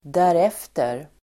Uttal: [där'ef:ter]